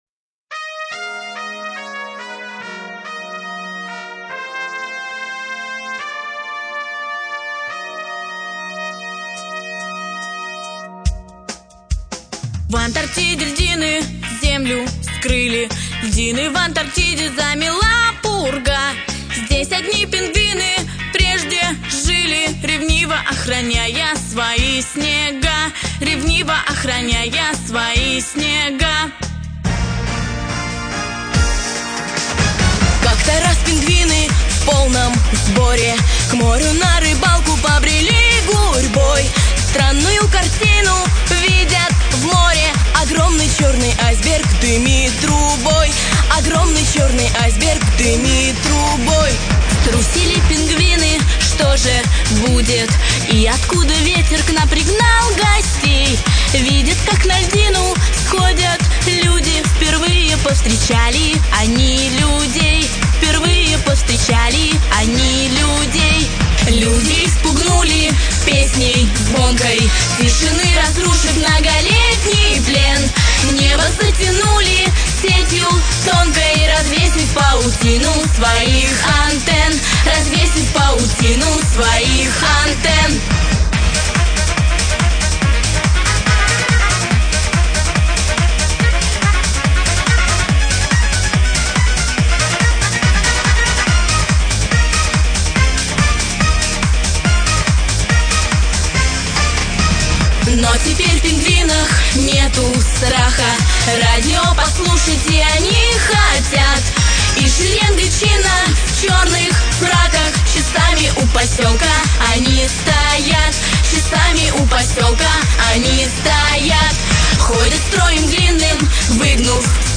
Главная » Музыка для детей » Детские песенки